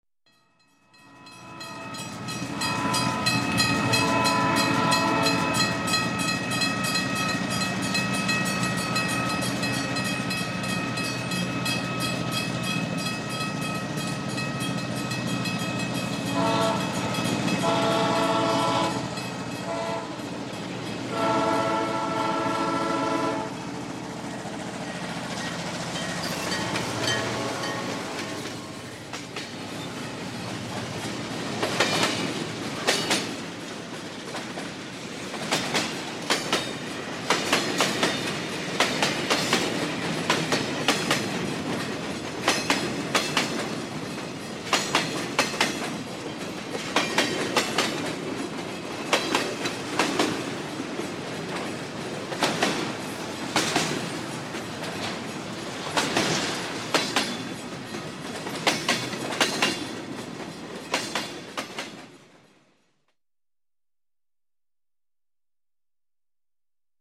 Звук ударов по рельсам - Альтернативная версия